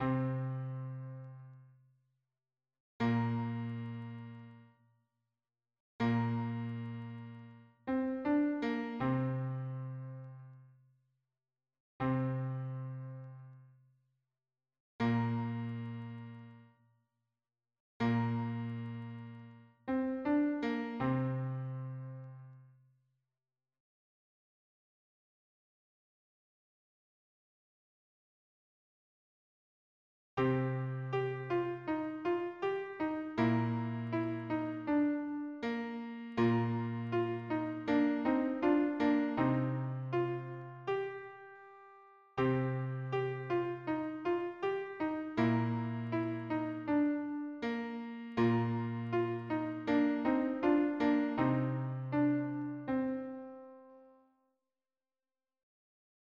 Meter: Irregular Key: c minor Source: Folk song